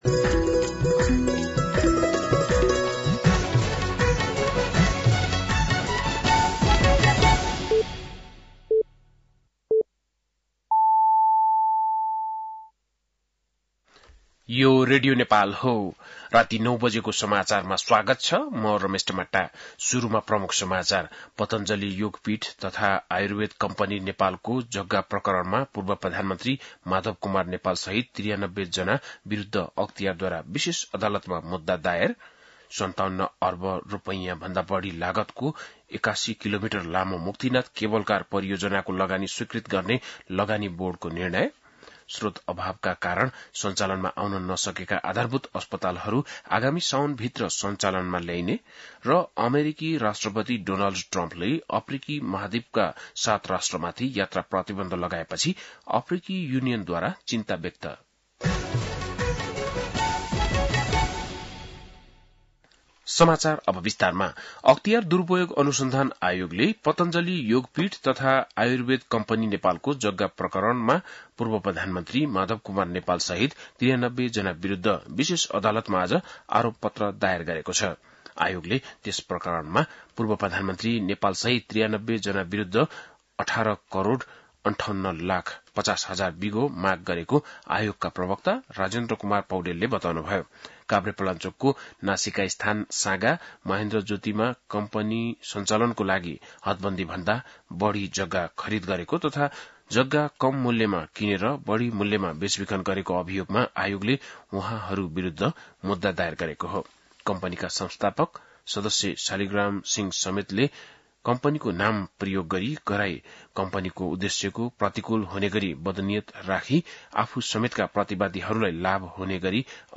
बेलुकी ९ बजेको नेपाली समाचार : २२ जेठ , २०८२
9-PM-Nepali-NEWS-2-22.mp3